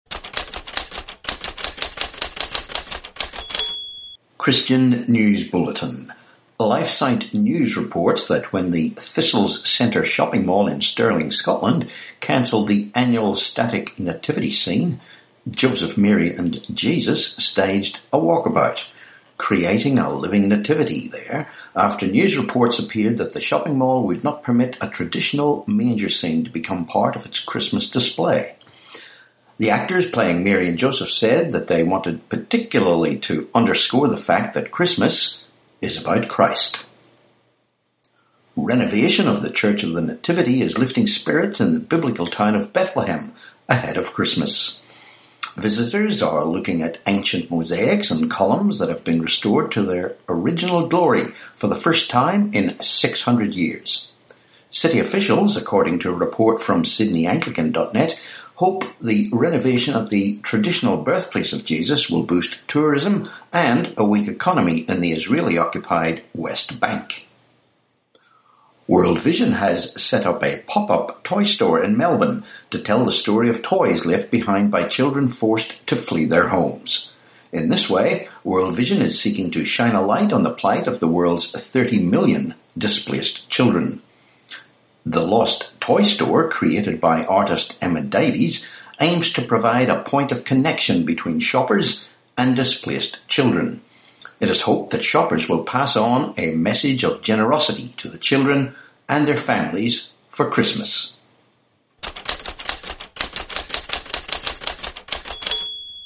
Christian News Bulletin, podcast